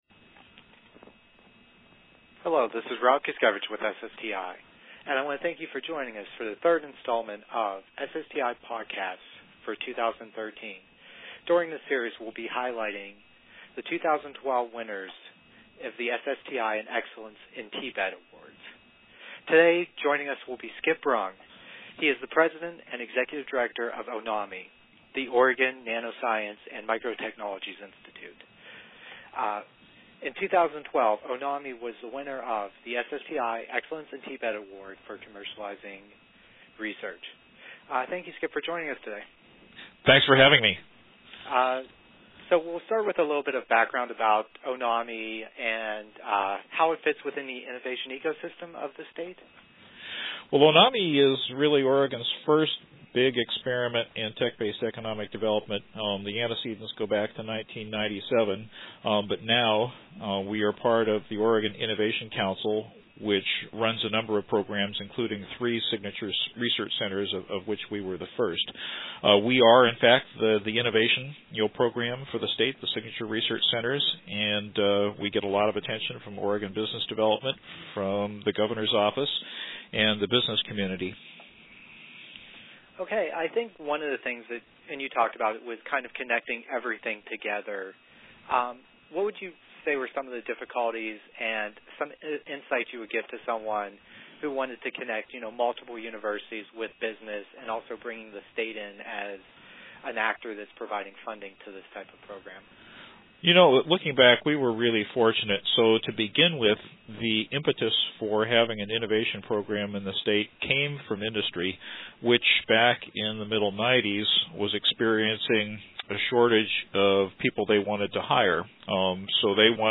SSTI Excellence in TBED Awards